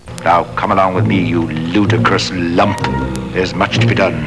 Dr. Smith calling the Robot a "Ludicrous lump"